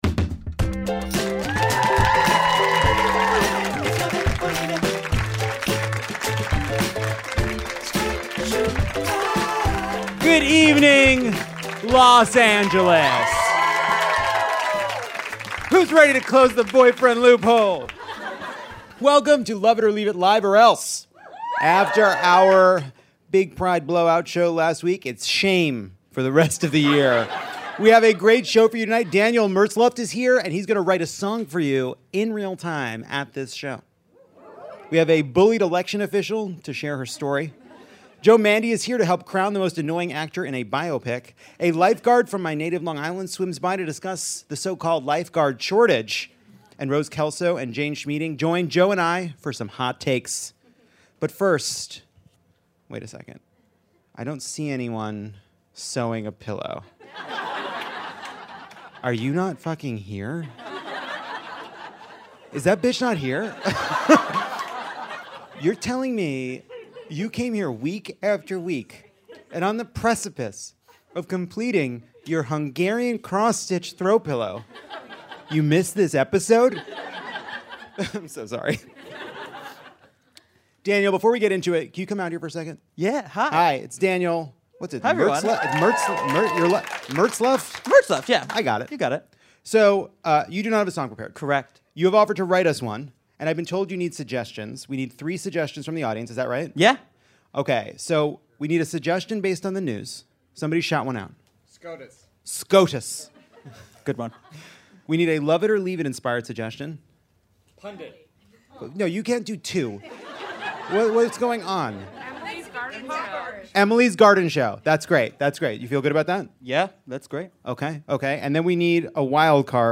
Lovett or Leave It welcomes you to Dynasty Typewriter, and to take a breath and recharge.